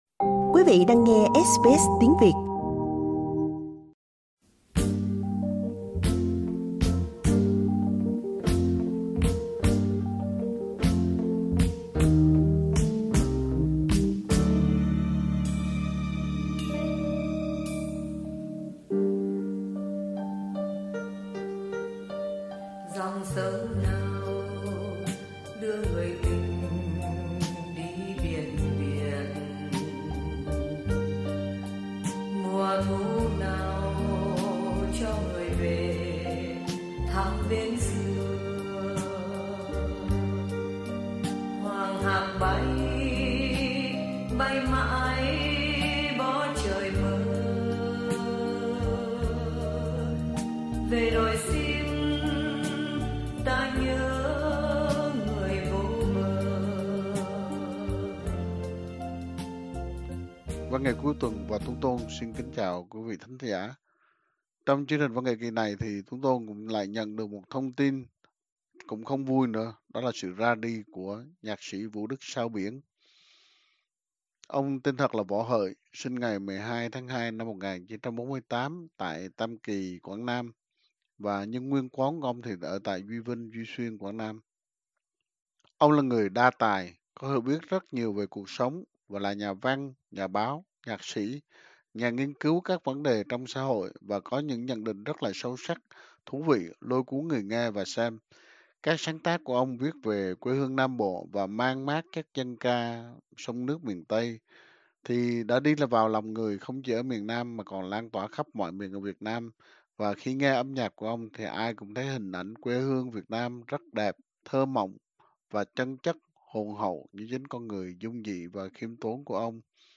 VNCT kỳ này xin giới thiệu đến quý thính giả một số nhạc phẩm tiêu biểu do ông sáng tác như một nét minh hoạ về câu chuyện đời của Ông. “Thu, hát cho người” được ông viết năm 68, khi chia tay với mối tình đầu để hành phương Nam tìm sự nghiệp.